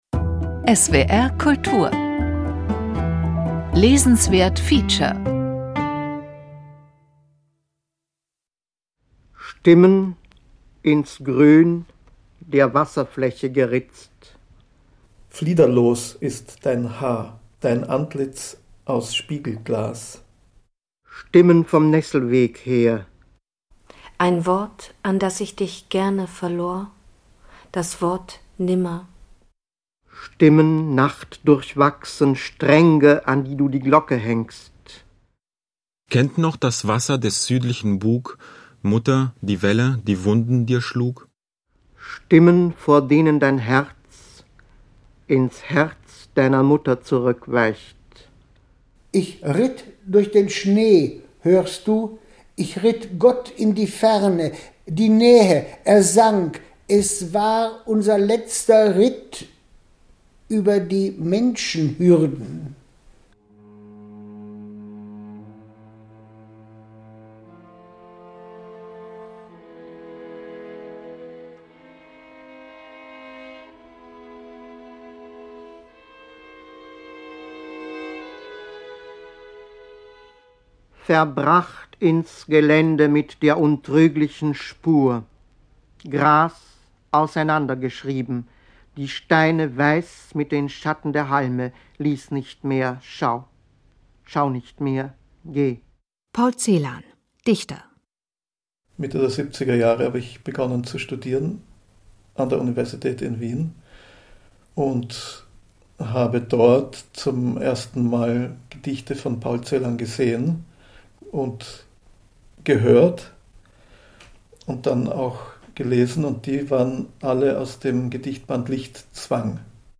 Musik: Ludwig van Beethoven, Streichquartett Nr. 15 in a moll, op. 132, 1. Satz